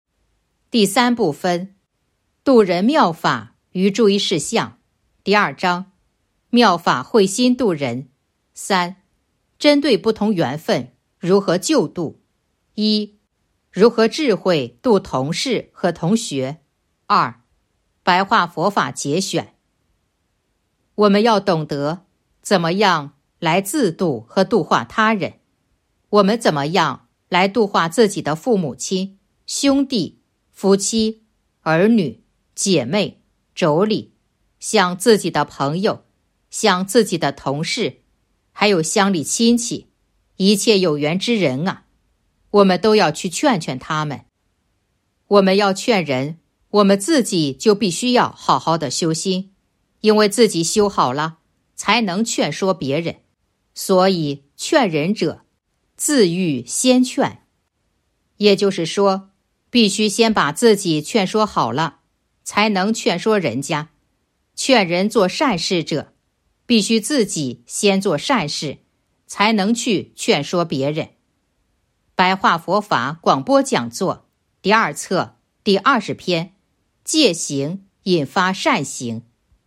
022.（一）如何智慧度同事和同学 2. 白话佛法节选《弘法度人手册》【有声书】